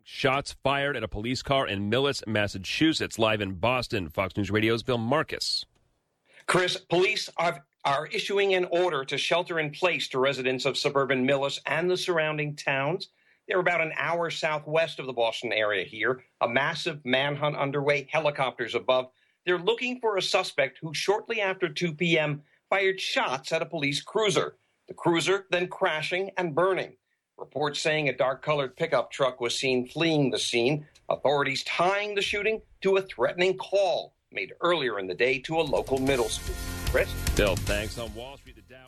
(BOSTON) FOX NEWS RADIO LIVE 5PM –
FOX-NEWS-RADIO-5PM-LIVE.mp3